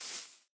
grass6.ogg